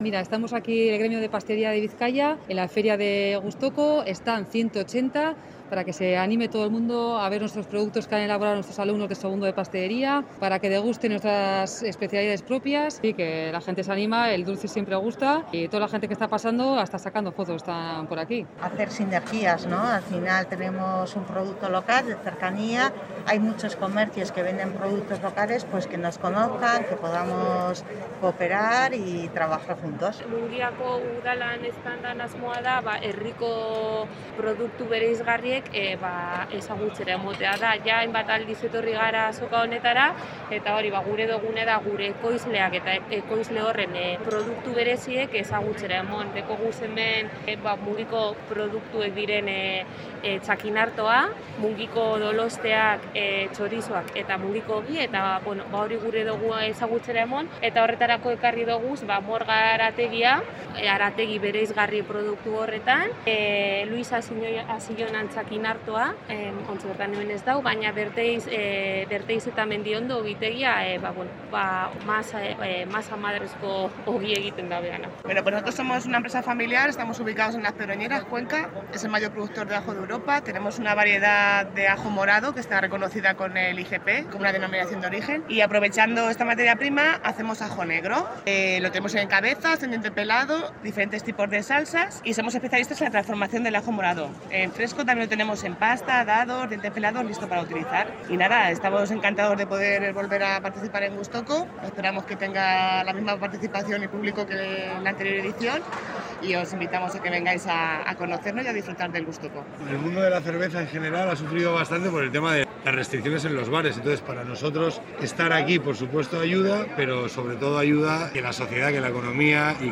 Onda Vasca ha hablado con algunos de los expositores. Esperan vender muchos productos y aprovechar este escaparate para dar a conocer sus empresas.